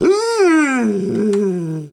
wolf-die-2.ogg